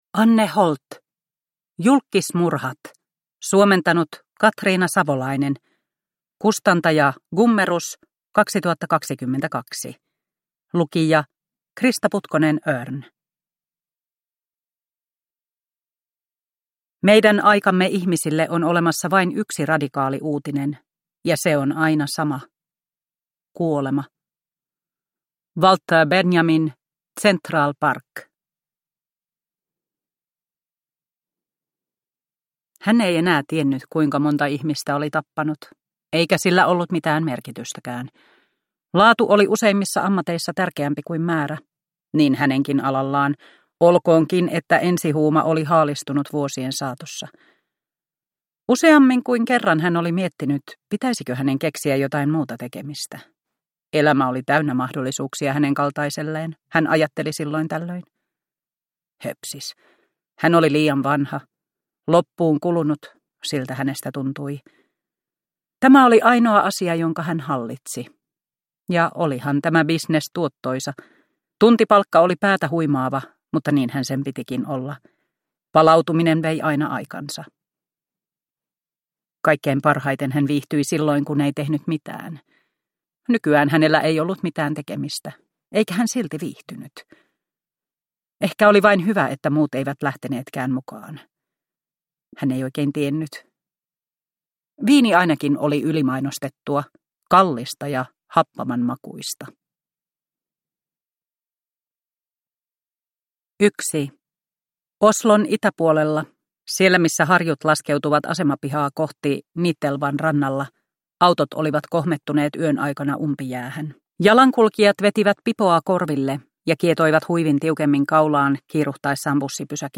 Julkkismurhat – Ljudbok – Laddas ner